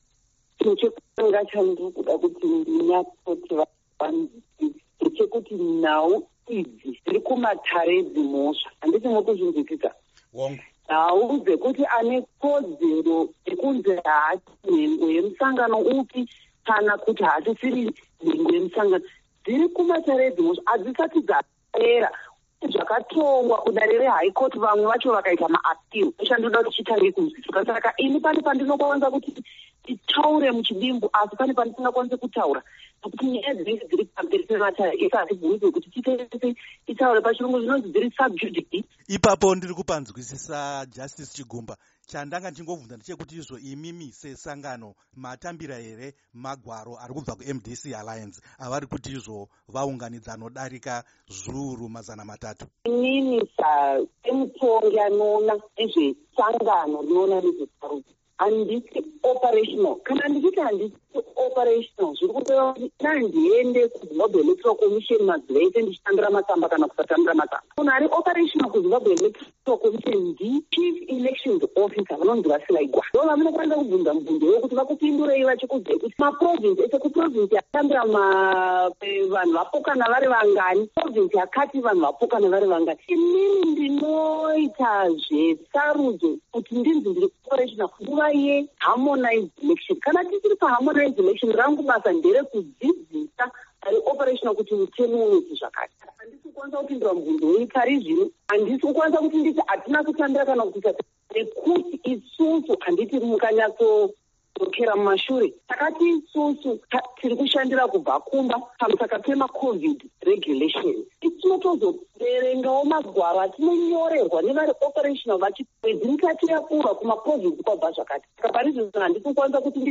Sachigaro veZimbabwe Electoral Commission, Justice Priscilla Chigumba, vaudza Studio7 parunhare kuti vanga vasati vasumwa nyaya iyi nemukuru anoona nezvesarudzo munyika, VaUtloile Silaigwana.
Hurukuro naJustice Priscilla Chigumba